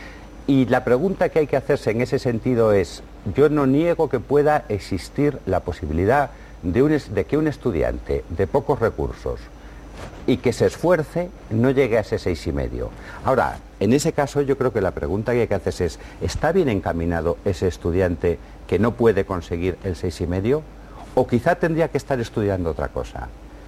Fragmento de una entrevista al ministro de Educación en TVE 24-6-13